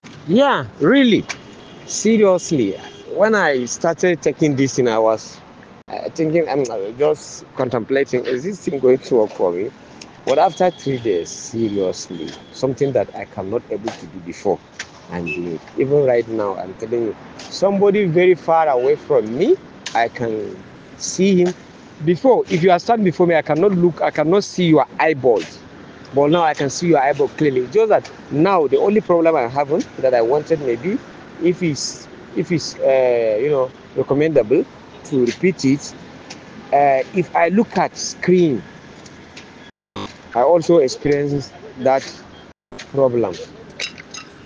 Verified Customer
Testimonial 1